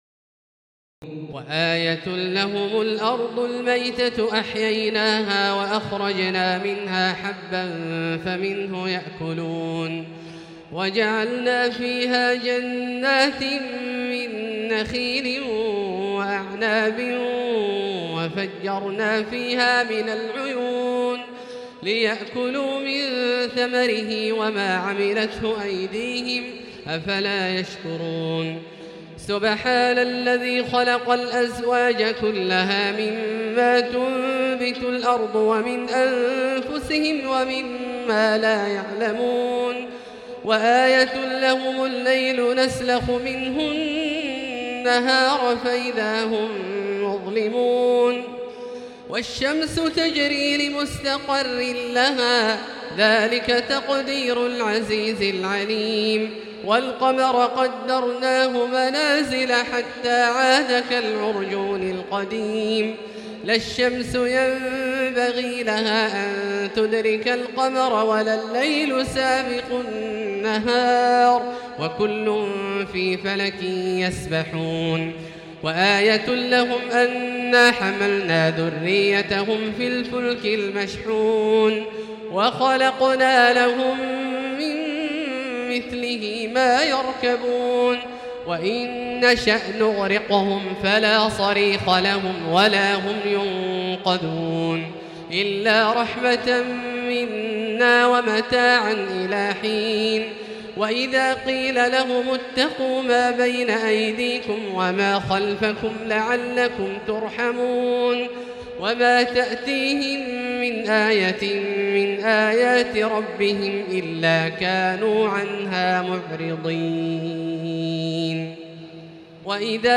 تراويح ليلة 22 رمضان 1438هـ من سور يس (33-83) والصافات(1-138) Taraweeh 22 st night Ramadan 1438H from Surah Yaseen and As-Saaffaat > تراويح الحرم المكي عام 1438 🕋 > التراويح - تلاوات الحرمين